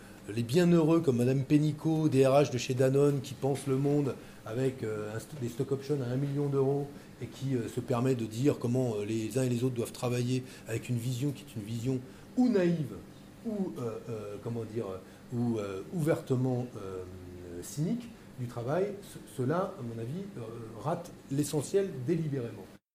De passage à Metz à l’occasion de son tour de France des universités, Benoît Hamon est venu s’exprimer en face des étudiants du Saulcy.
C’était aussi l’occasion pour les étudiants de montrer leur soutien à l’ancien candidat à l’élection présidentielle, l’homme ayant droit à une impressionnante salve d’applaudissements dès son apparition sur scène.